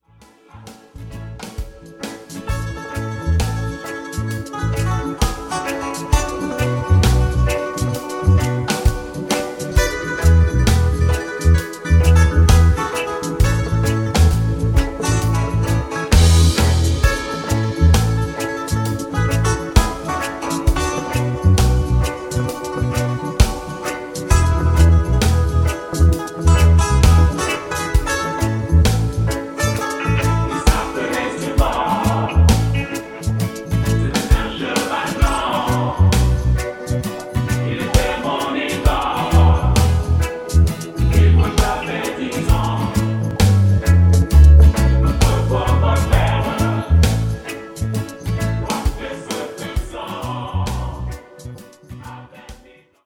version reggae